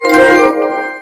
Ps5 Platinum Trophy - サウンドボタン
ps5-platinum-trophy.mp3